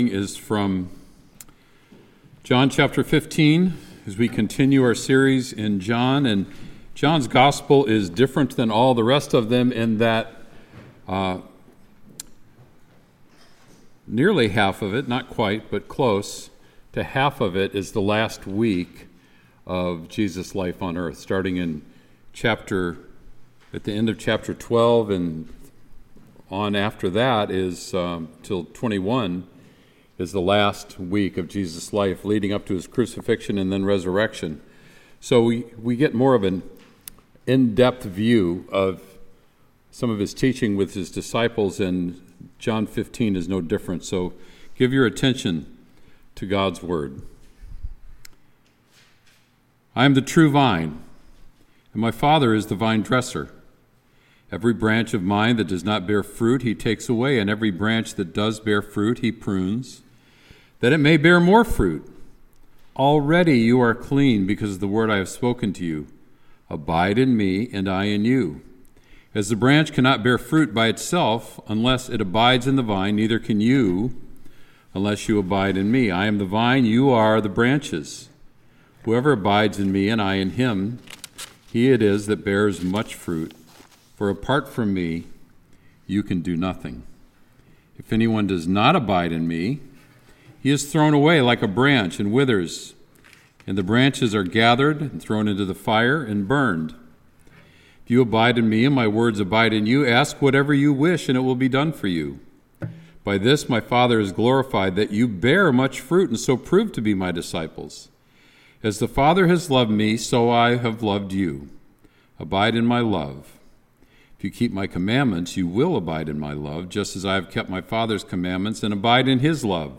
Sermon “Vine and Branches”